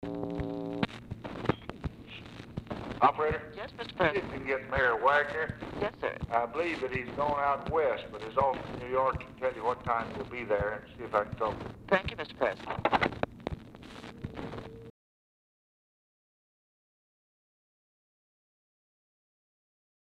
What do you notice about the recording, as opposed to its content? Oval Office or unknown location Dictation belt